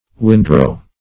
Windrow \Wind"row`\, n. [Wind + row.]